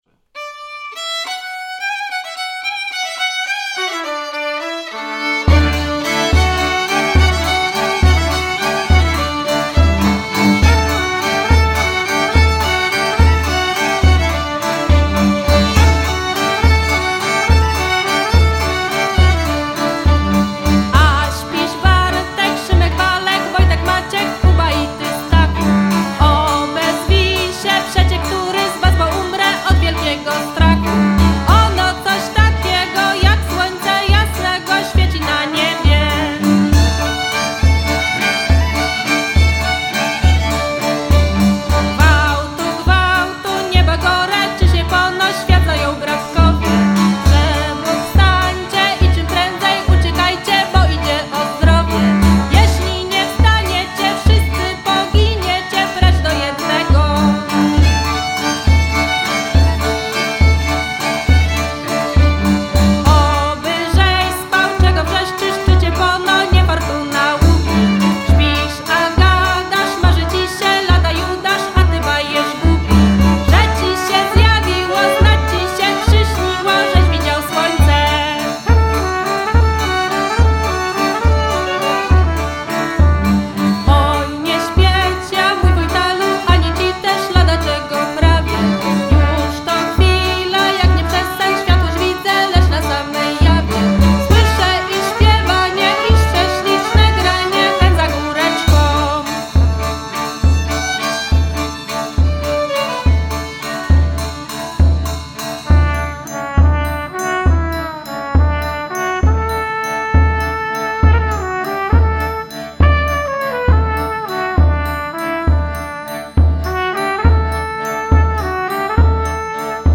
Kożuch
Kolęda w opracowaniu zespołu, melodia z Łęczyckiego.
Kolęda